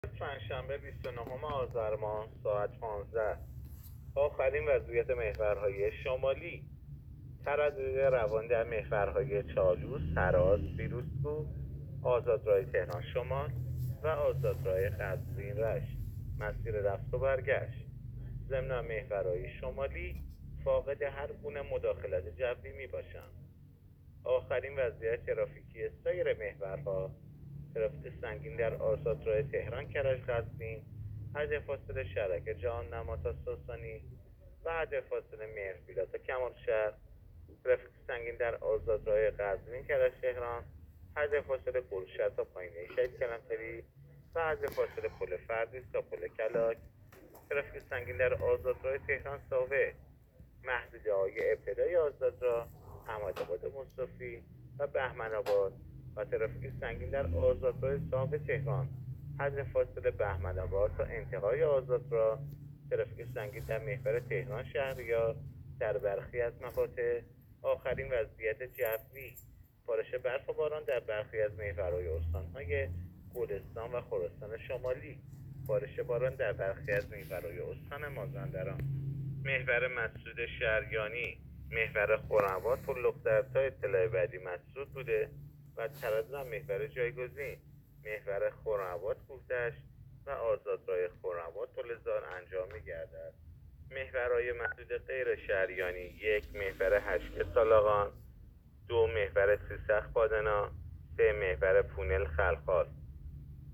گزارش رادیو اینترنتی از آخرین وضعیت ترافیکی جاده‌ها تا ساعت ۱۵ بیست و نهم آذر؛